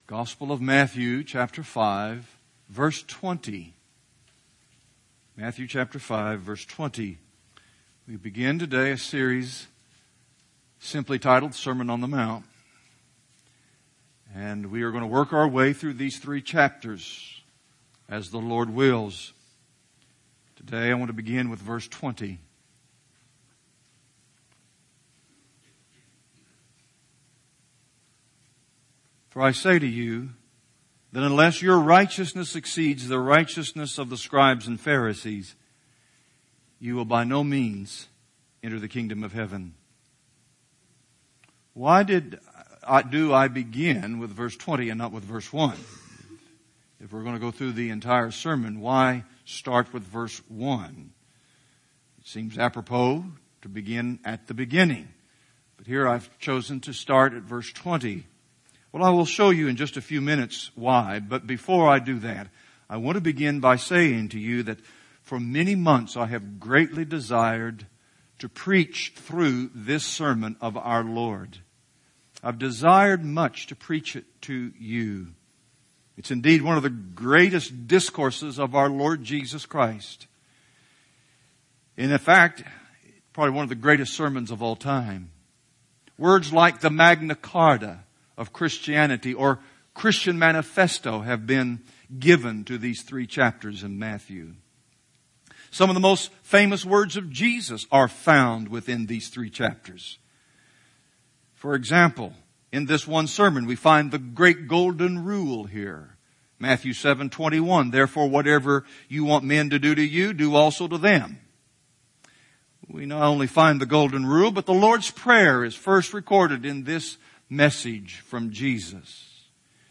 Introduction to the Sermon | Real Truth Matters